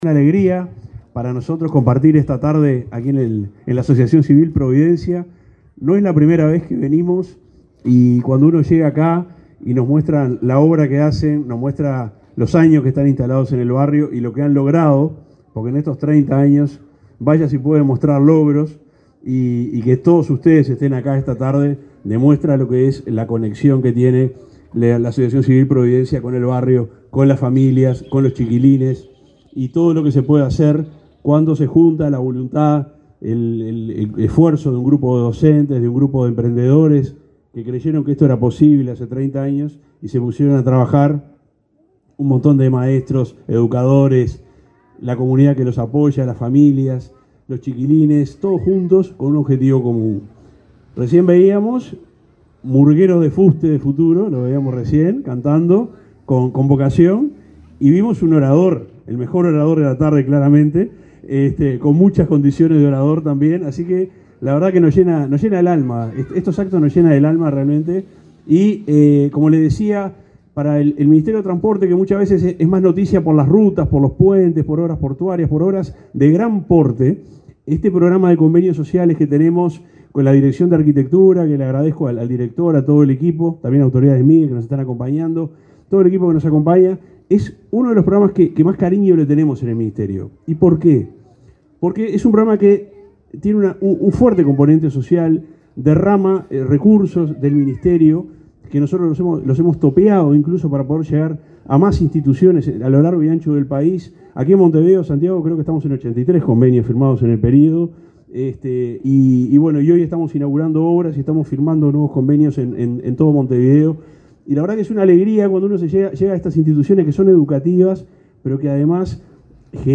Palabras del subsecretario de Transporte, Juan José Olaizola
Palabras del subsecretario de Transporte, Juan José Olaizola 12/09/2024 Compartir Facebook X Copiar enlace WhatsApp LinkedIn El Ministerio de Transporte y Obras Públicas y el Centro Educativo Providencia inauguraron las obras de ampliación del Club de Niños, en el barrio Casabó de Montevideo. El subsecretario Juan José Olaizola, destacó en su alocución la importancia de esa infraestructura.